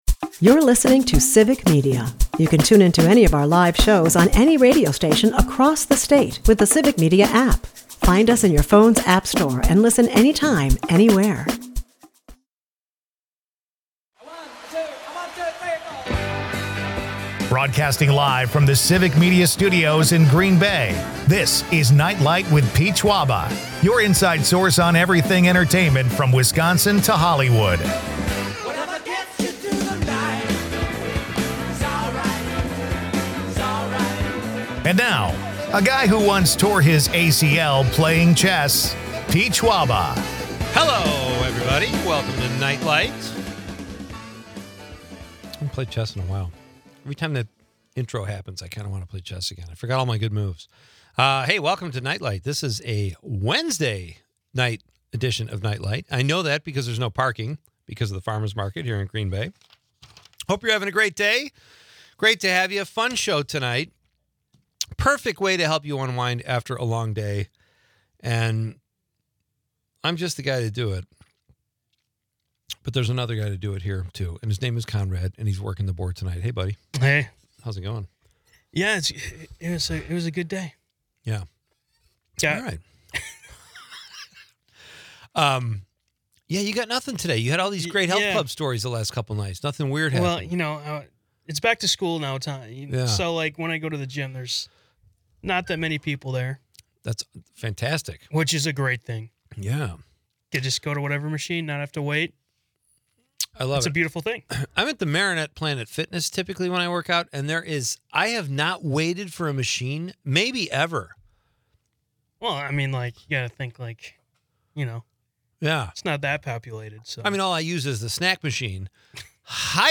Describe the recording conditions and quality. Ed Gein the Musical gets a stage revival, promising a unique mix of horror and humor. The episode is peppered with audience interactions about must-watch movies, from 'Shawshank Redemption' to 'Star Wars,' and a sprinkle of gym banter.